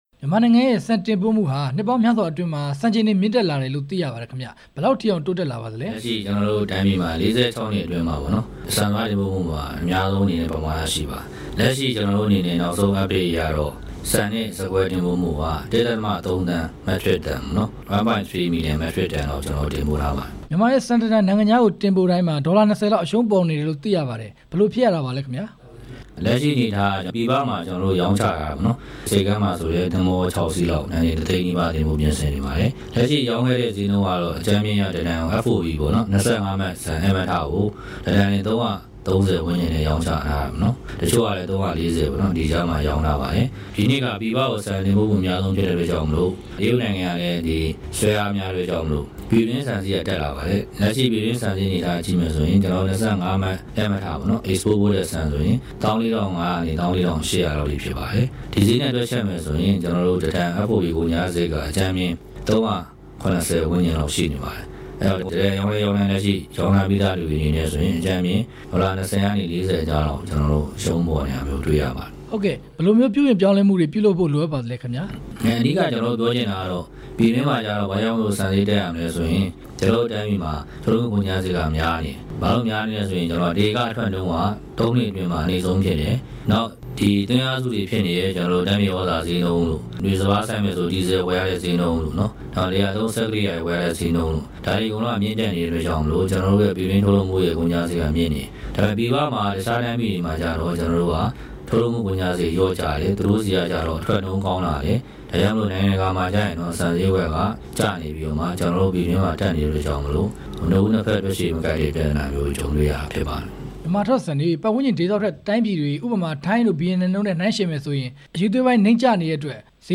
ပြည်ပဆန်တင်ပို့မှု မြင့်တက်လာတာနဲ့ ပတ်သက်ပြီး မေးမြန်းချက်